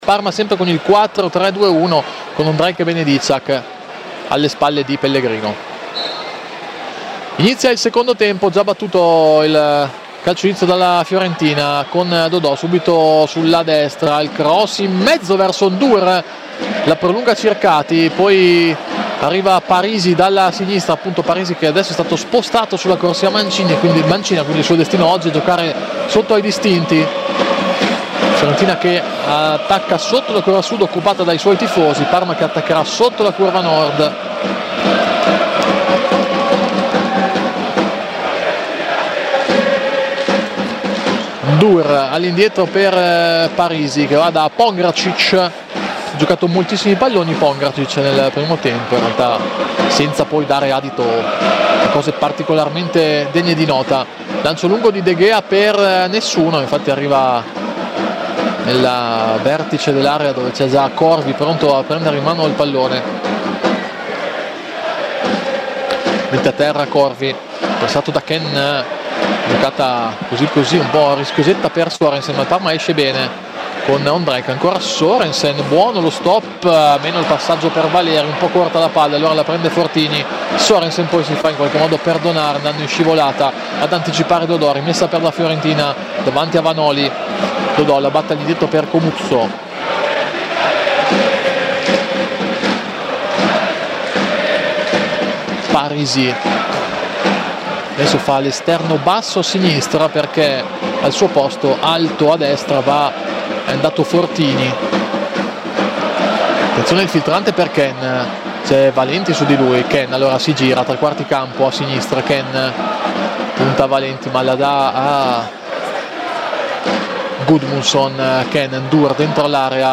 Radiocronaca